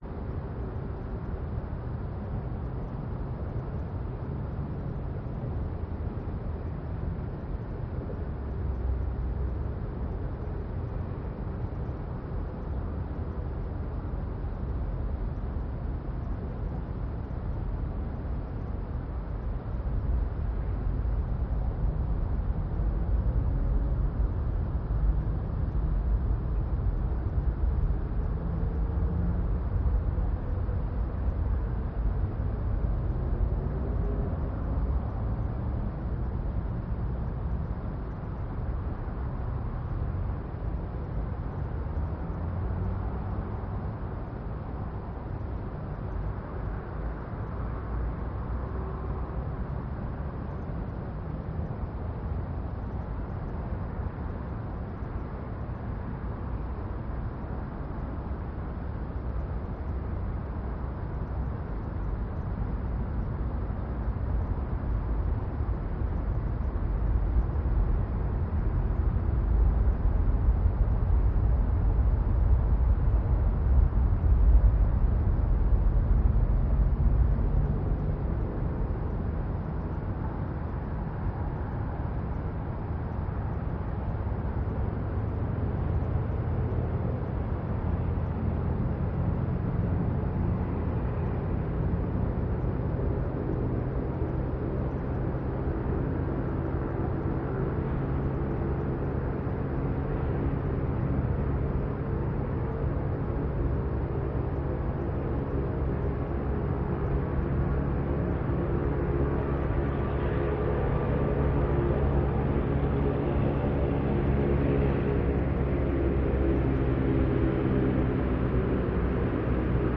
Звуки атмосферы
Москва весенней ночью: парк Воробьевы горы, далекий метропонт с поездами, шум движения и аварийные газы